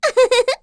Pansirone-Vox-Laugh.wav